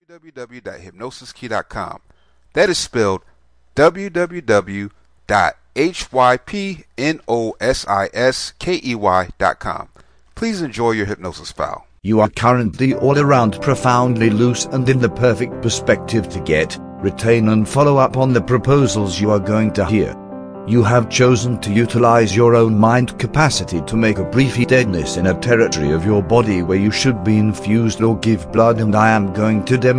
Numbness Relaxation Self Hypnosis Mp3